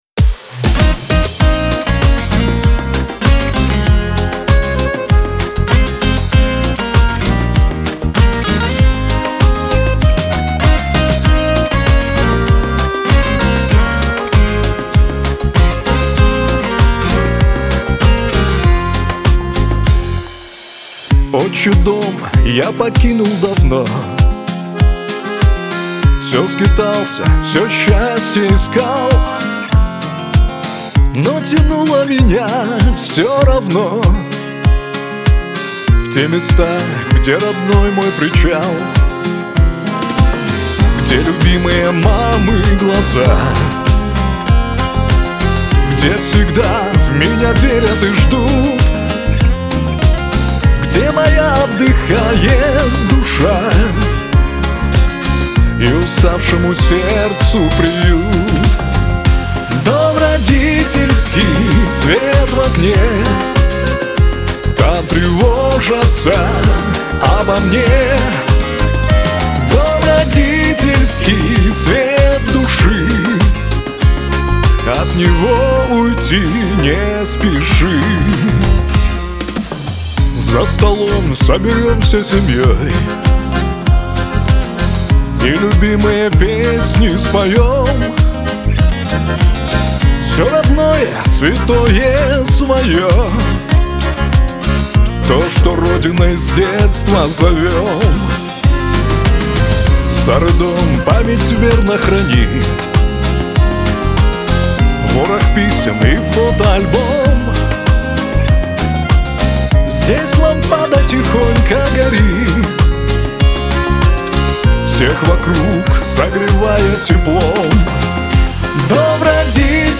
грусть
Шансон
Лирика